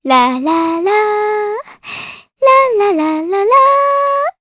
Juno-Vox_Hum_jp.wav